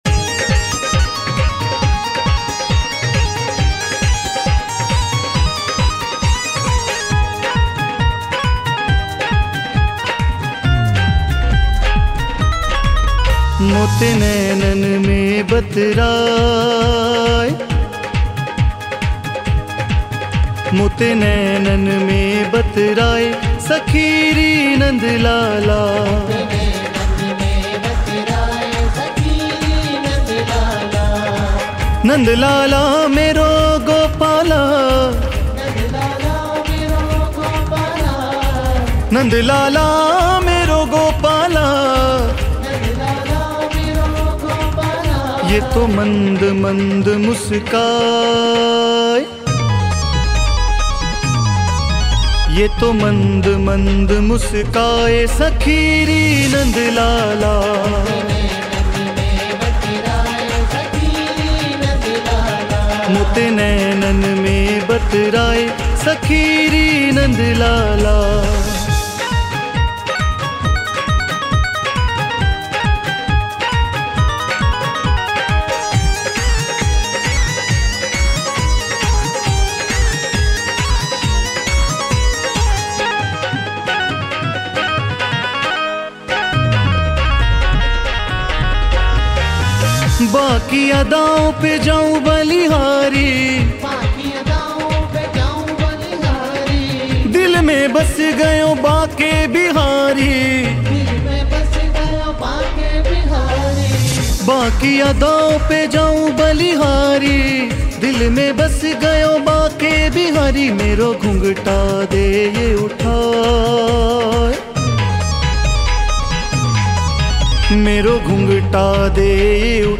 Krishna Bhajan